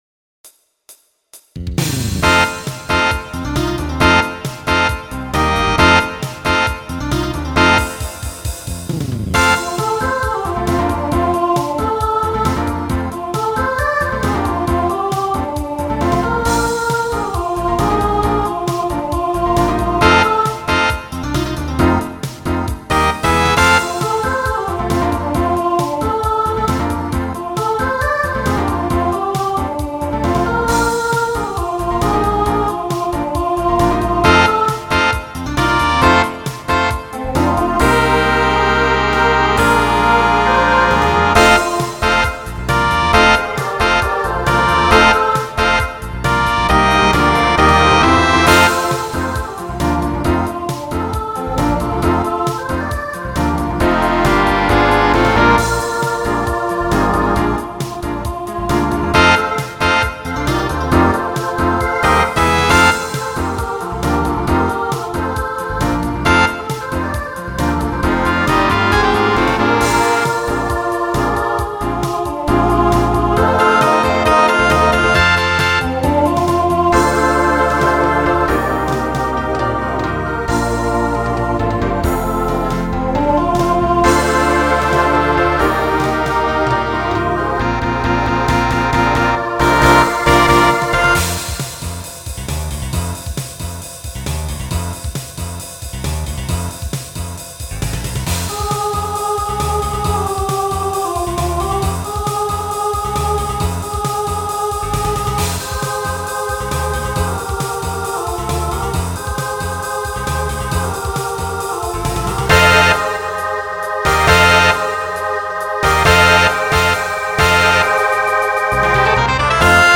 Voicing SSA Instrumental combo Genre Broadway/Film